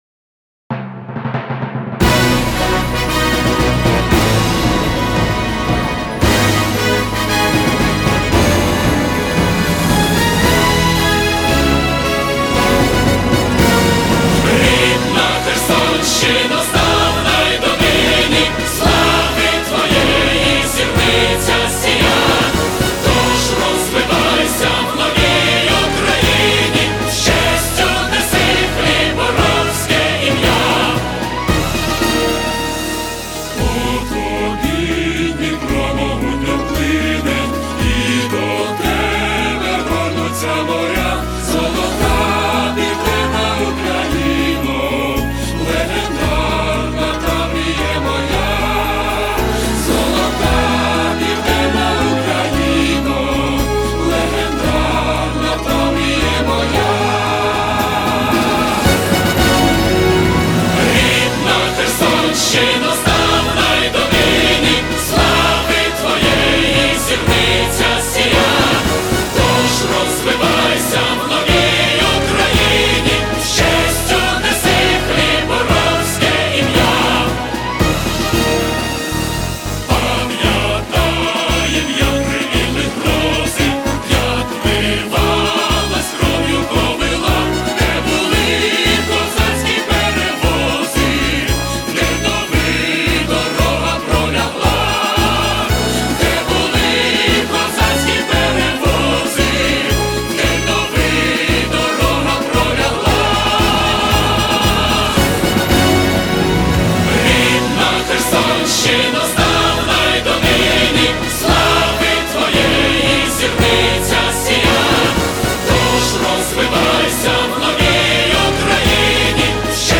Кстати текст в исполнении и печатный - немного отличаются.